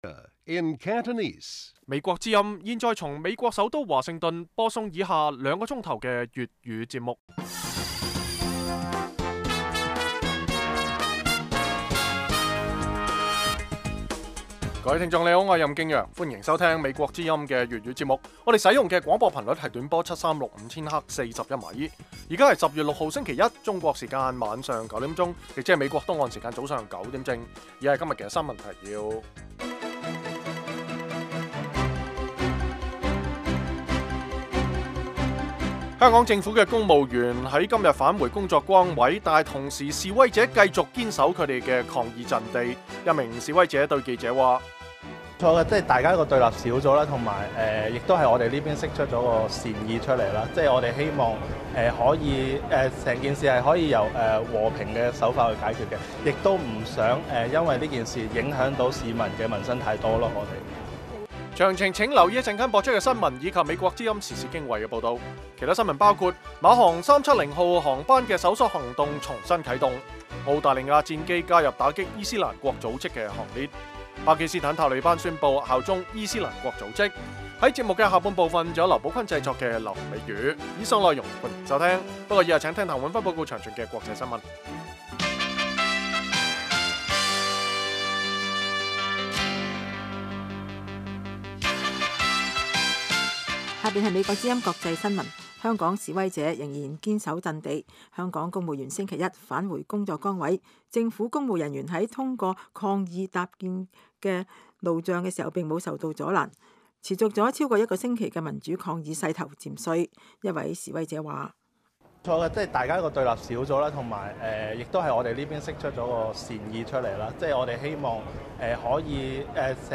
每晚 9點至10點 (1300-1400 UTC)粵語廣播，內容包括簡要新聞、記者報導和簡短專題。